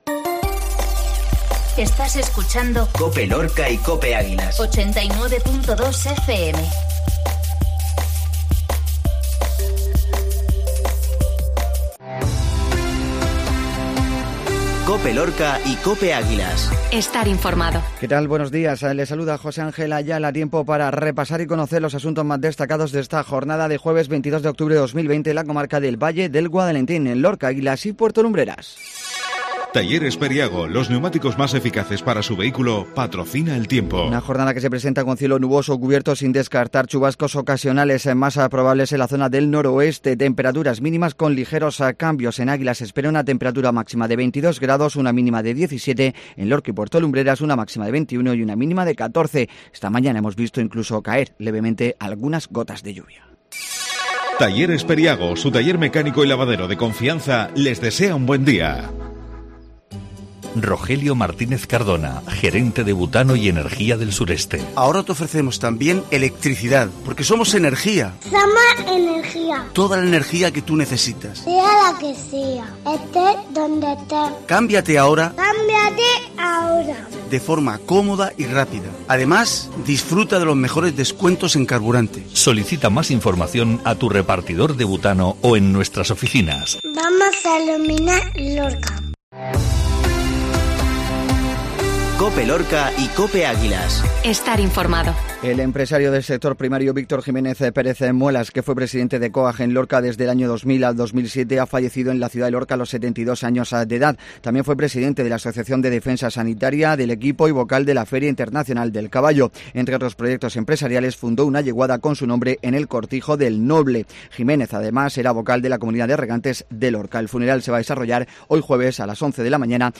INFORMATIVO MATINAL JUEVES